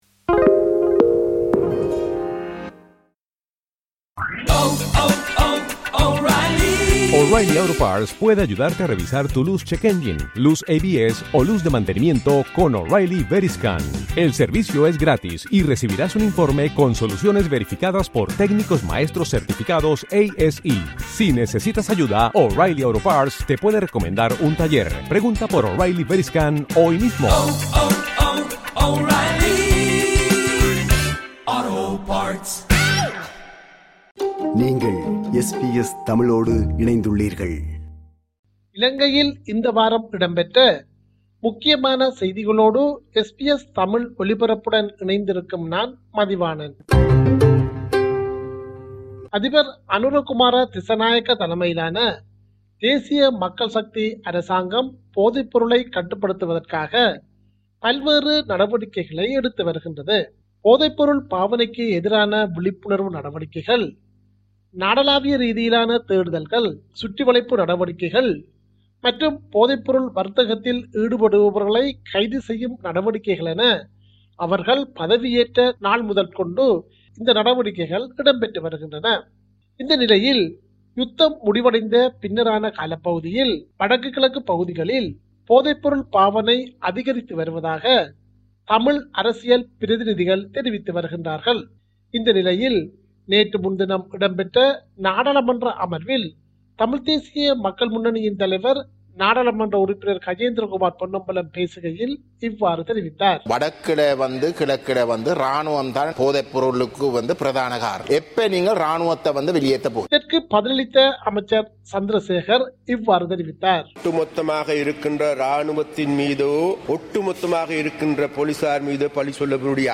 இலங்கை: இந்த வார முக்கிய செய்திகள்
Top news from Sri Lanka this week To hear more podcasts from SBS Tamil, subscribe to our podcast collection.